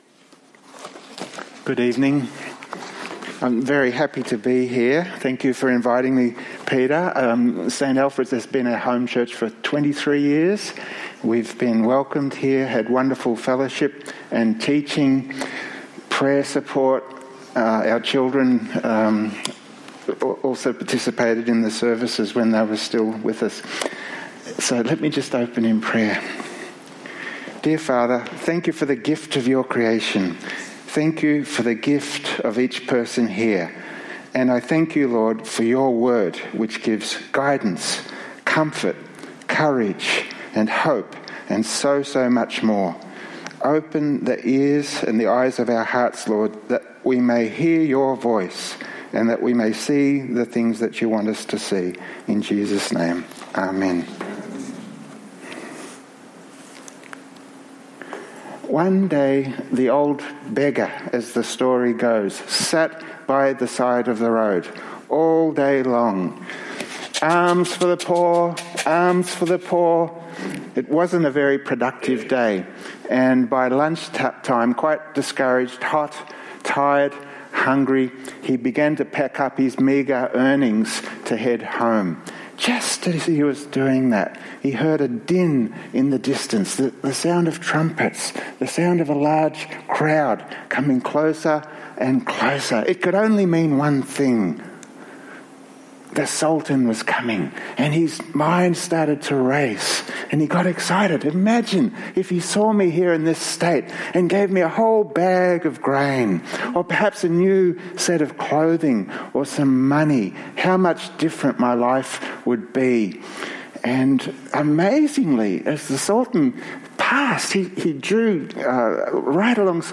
Sermons | St Alfred's Anglican Church
In this sermon, Tony Rinaudo speaks on the theme of 'The Forest Underground (6pm)' as part of the series 'Standalone Sermon'. The bible reading is Proverbs 8:27-31, Ephesians 2:8-10.